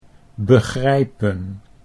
Words of 3 or more syllables may contain both a voiceless-E prefix and a suffix, or two voiceless-E prefixes or suffixes: